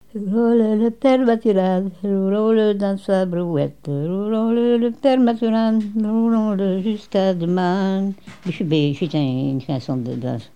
rondes enfantines
Chansons et formulettes enfantines
Pièce musicale inédite